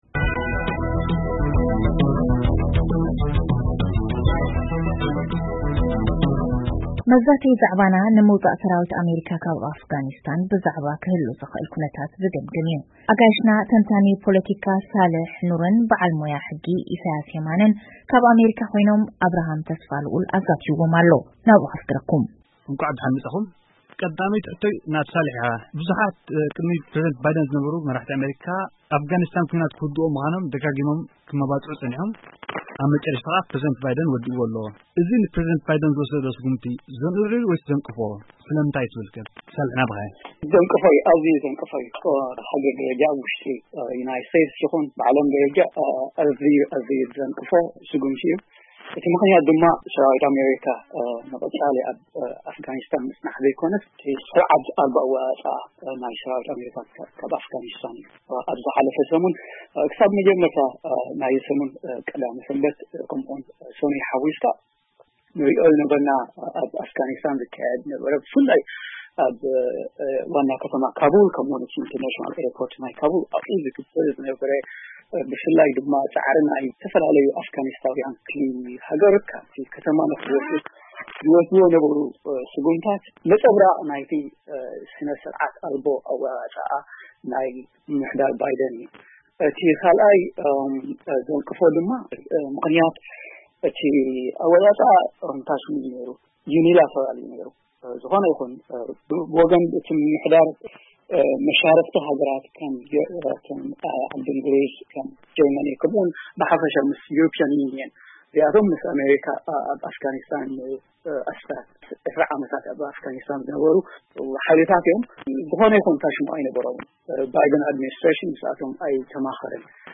ኣፍጋኒስታን ኣብ ቃራና መገዲ፡ ዘተ ብጠመተ ታንታኒ ፖለቲካን ብዓይኒ ክኢላ ሕጊን